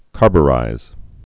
(kärbə-rīz, -byə-)